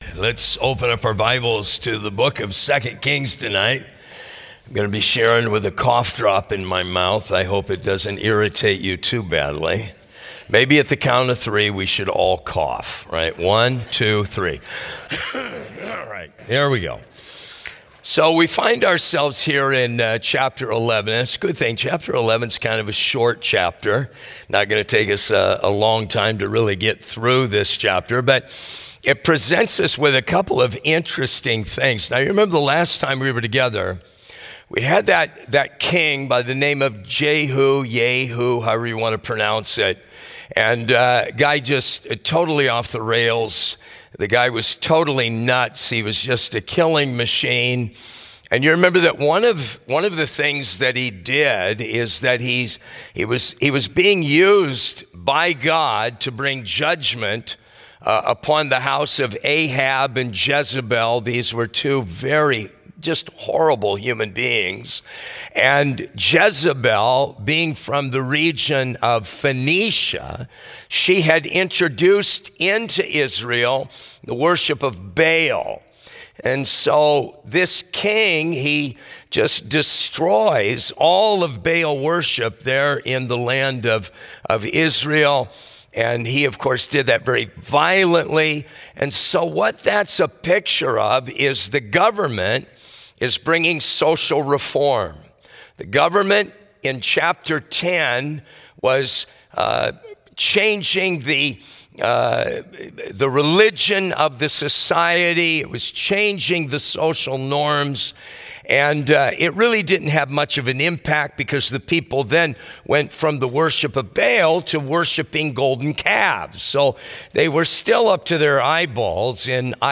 A study in 2 Kings 11 from our Midweek Service at Harvest Fellowship